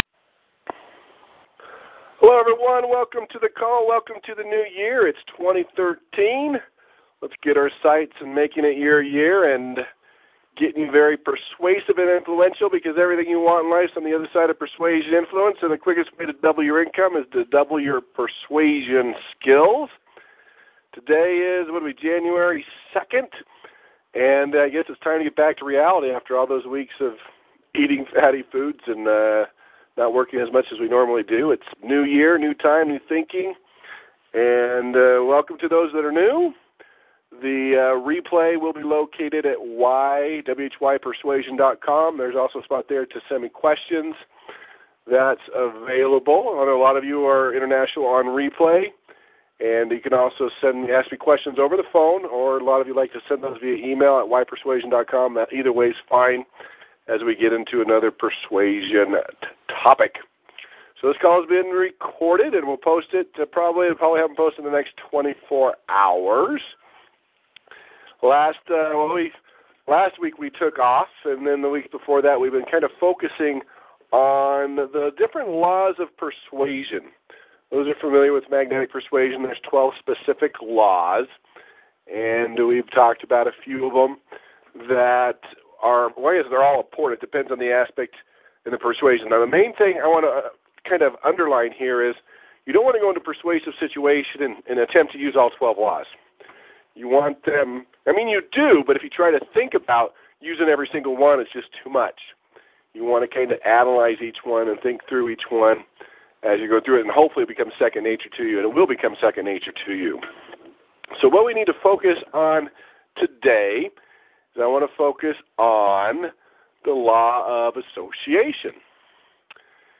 ‹ Law of Scarcity Listening vs Hearing › Posted in Conference Calls